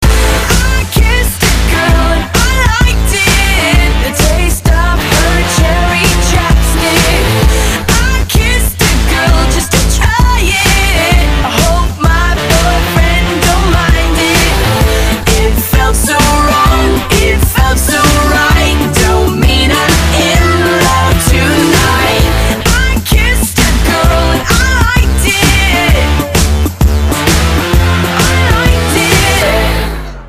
Категория: Танцевальные